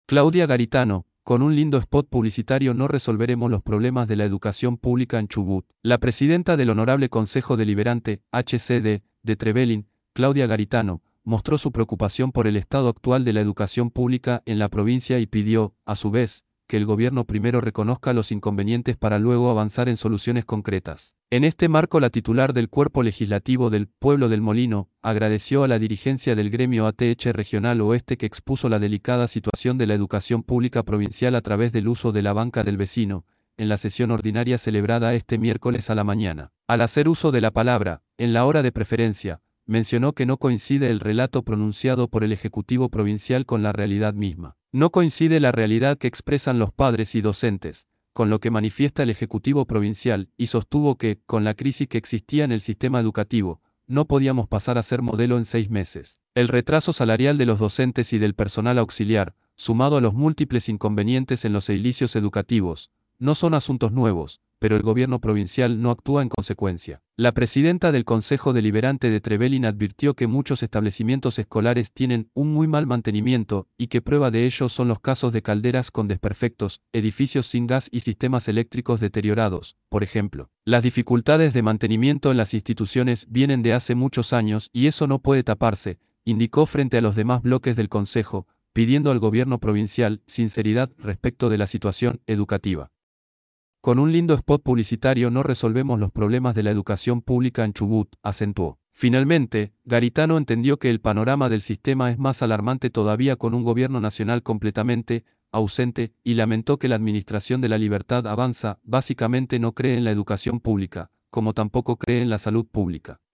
Al hacer uso de la palabra, en la Hora de Preferencia, mencionó que no coincide el relato pronunciado por el Ejecutivo Provincial con la realidad misma.